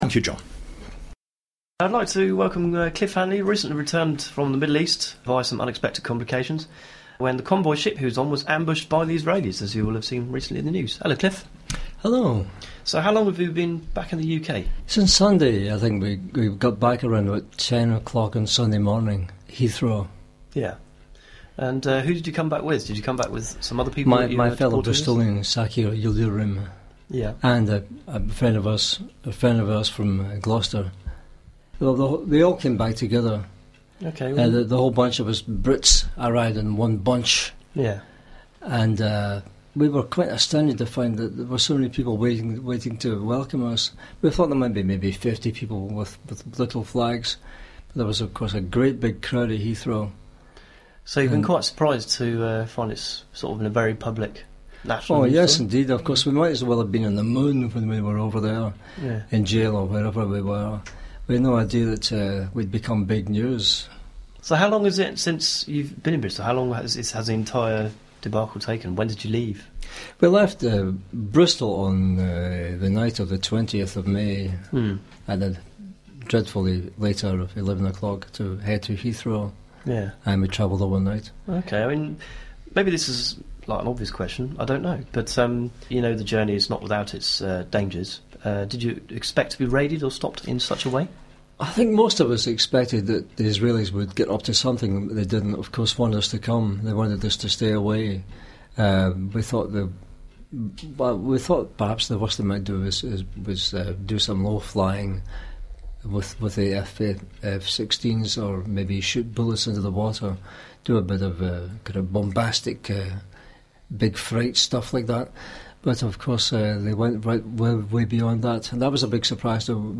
Our main activity is our weekly current affairs and arts magazine programme Dialect, which is recorded at our Queen's Square studios and posted for download every week on Friday morning.
mp3 10M Excerpted interview of Flotilla crew member from Bristol's Dialect Radio